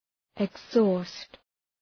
Προφορά
{ıg’zɔ:st}